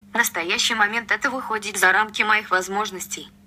Звуки Siri
Голос Siri пока не поддается изменению